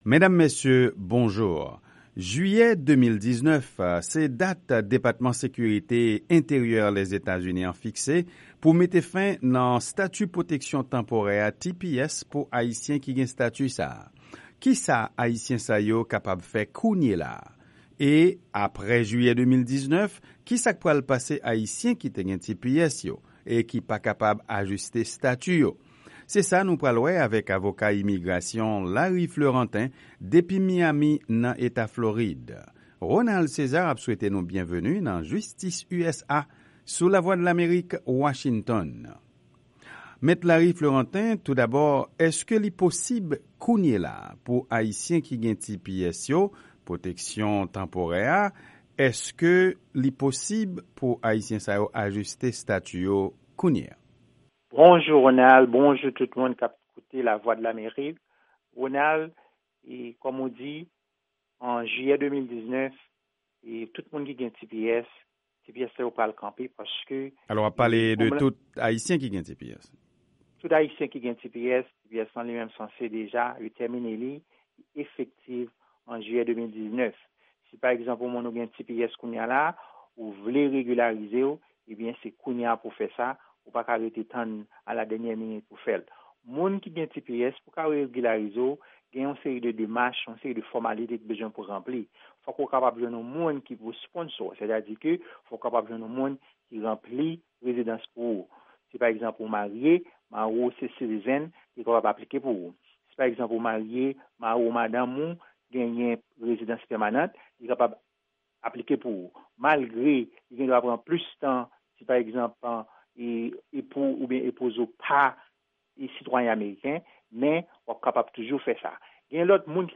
Entèvyou sou TPS ak Avoka Imigrasyon